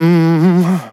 Categories: Vocals Tags: DISCO VIBES, dry, english, fill, male, MMMM, sample
man-disco-vocal-fills-120BPM-Fm-8.wav